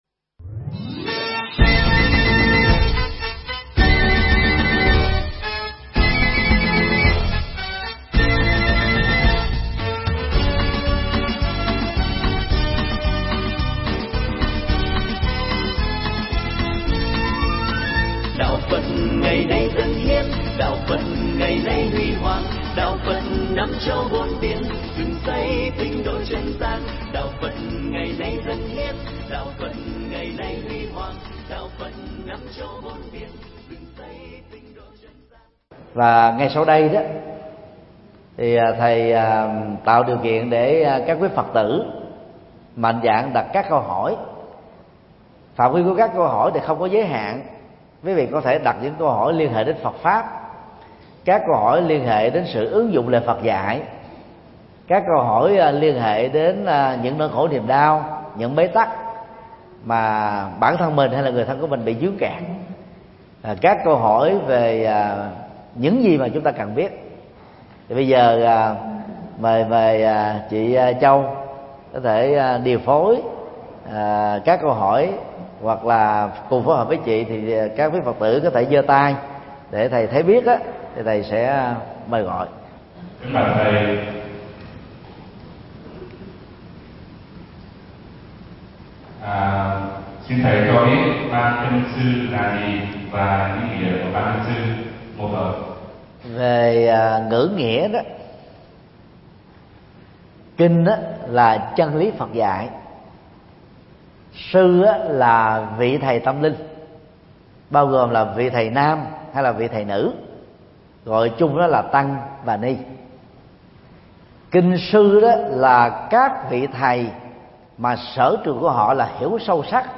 Nghe mp3 Vấn Đáp: Ý Nghĩa Ban Kinh Sư – Vượt Qua Bệnh Cố Chấp – Thượng Tọa Thích Nhật Từ giảng tại Center Wedding Conference Golden Palace (Đồng Nai), ngày 3 tháng 8 năm 2017